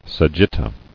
[Sa·git·ta]